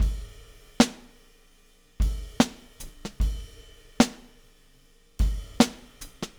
Weathered Beat 05.wav